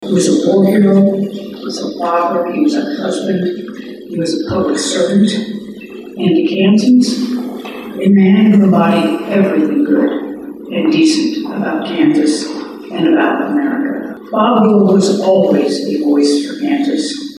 The Kansas Farm Bureau hosted its annual meeting Sunday, with Gov. Laura Kelly as the key guest speaker.